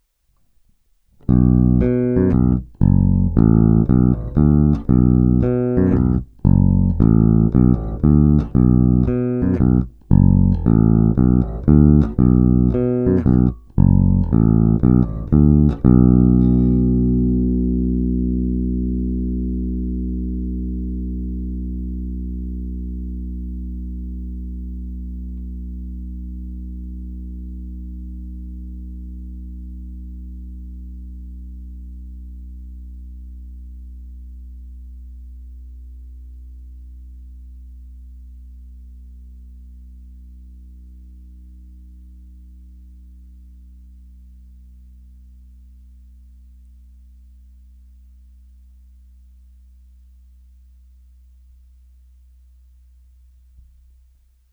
Zvuk má očekávatelně moderní charakter, je pevný, konkrétní, vrčí, má ty správné středy důležité pro prosazení se v kapele.
Není-li uvedeno jinak, následující nahrávky jsou provedeny rovnou do zvukové karty a s korekcemi na středu a dále jen normalizovány, tedy ponechány bez postprocesingových úprav.
Oba snímače